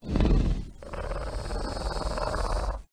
Звуки раптора
Звук дыхания раптора